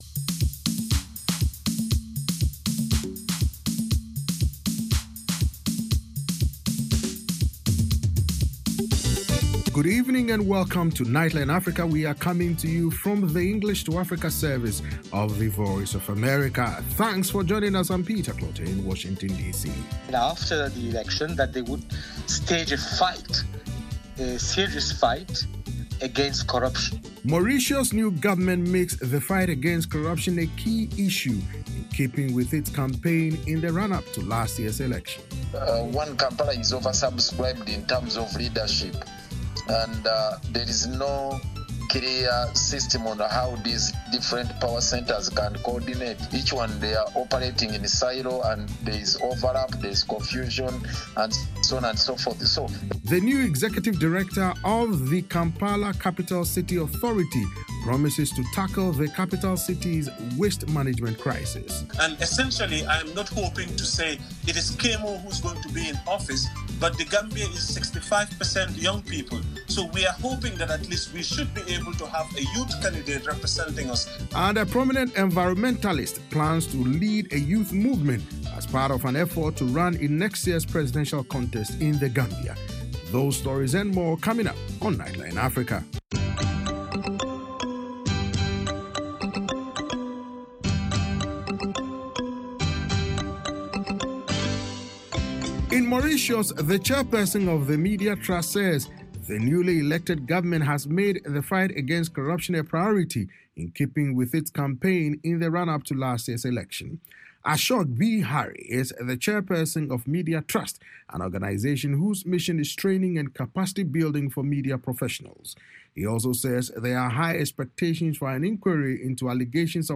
Nightline Africa is a 60-minute news magazine program highlighting the latest issues and developments on the continent. Correspondents from Washington and across Africa offer in-depth interviews, analysis and features on African arts and culture, sports, and music